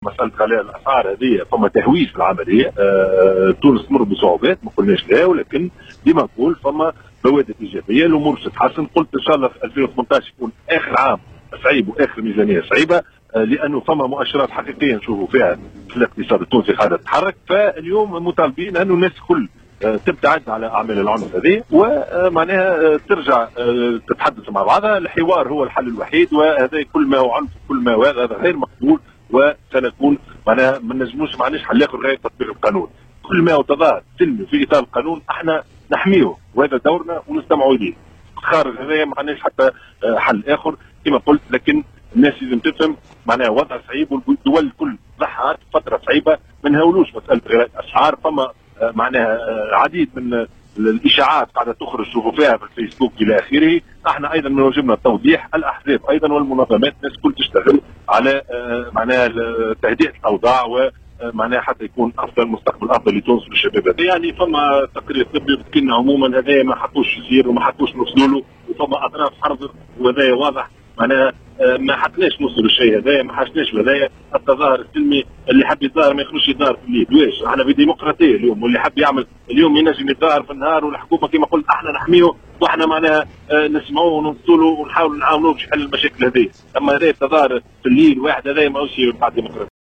Dans une déclaration de presse accordée en marge de sa visite d'inspection à la caserne de Remada, Chahed est revenu sur les horaires des manifestations.